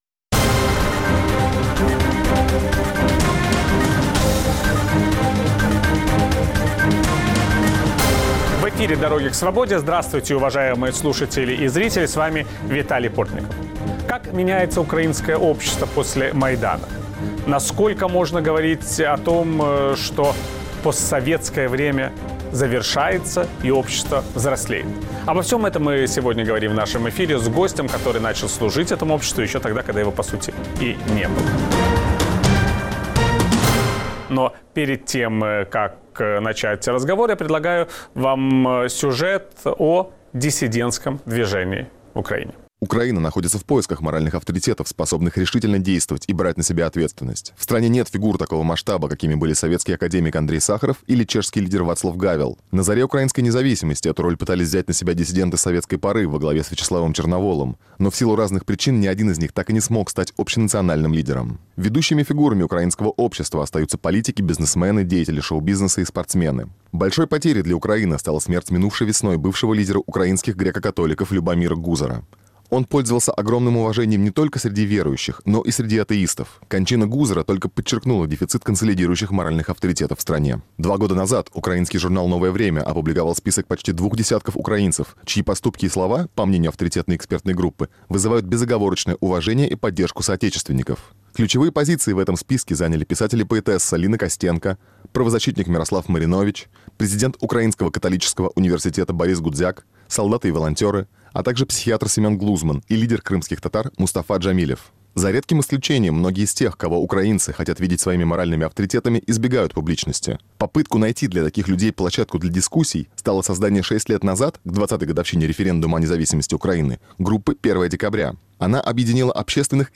Есть ли нравственные авторитеты в украинском обществе? Кто оказывает влияние на граждан после Майдана? Виталий Портников беседует с украинским правозащитником и диссидентом советских времен Семеном Глузманом.